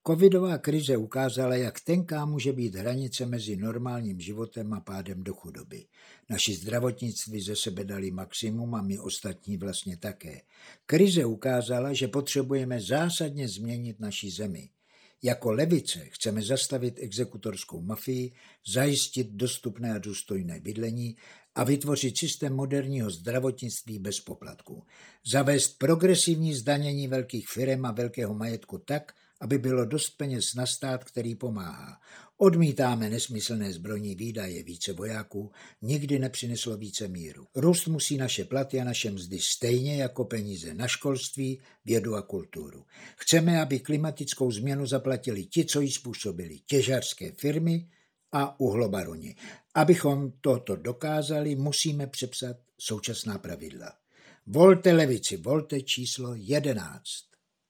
Předvolební audiospot Levice pro Český rozhlas